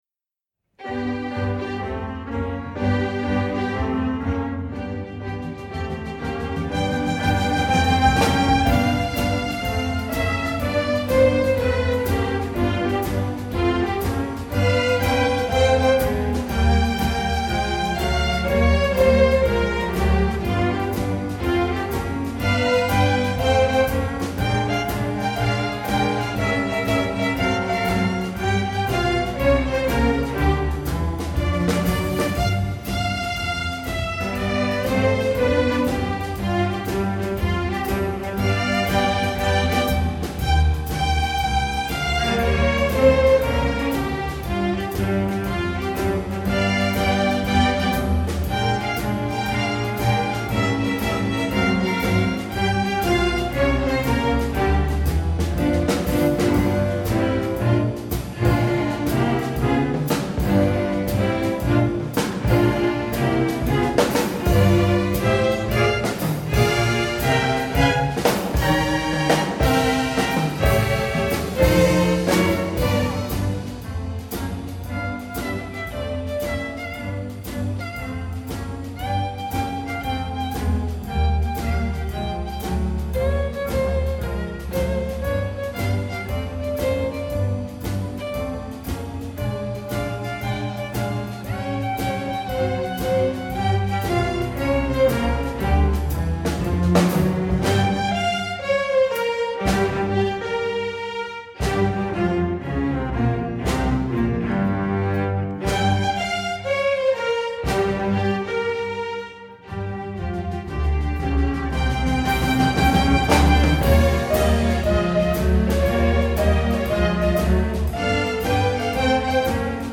Voicing: String Orchestra W